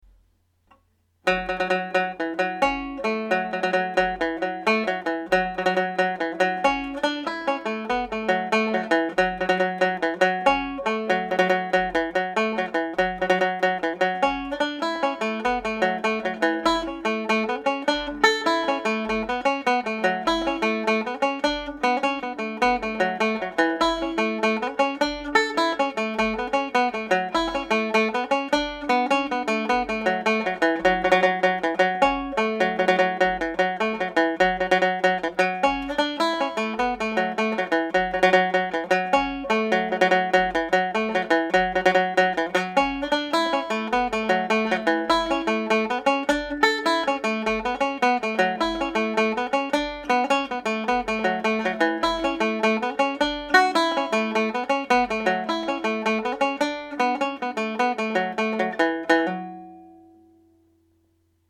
Cathal McConnells slip jig played at normal speed